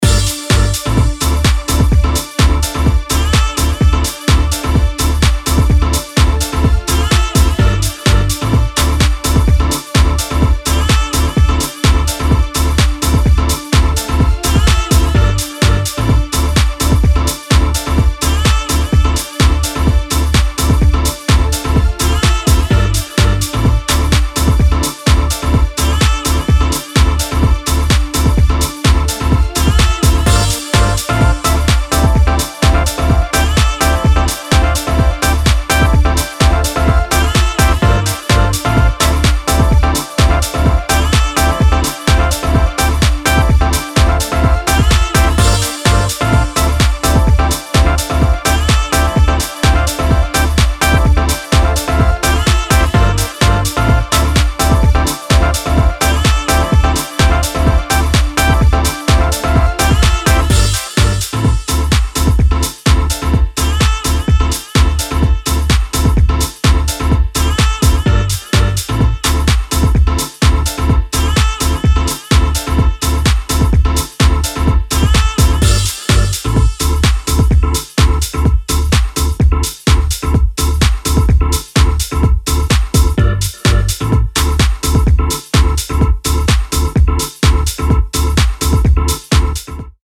新旧のハウス・ファンに手放しで推せる、充実の内容です。